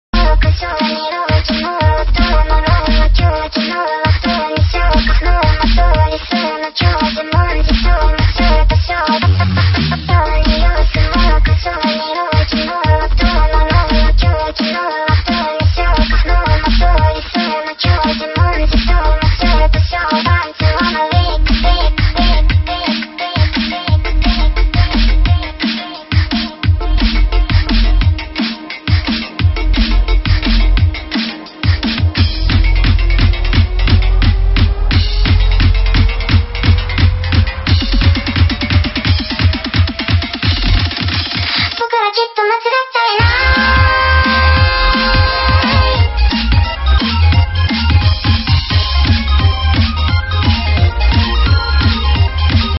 涉及术力口本家和泛术力口歌姬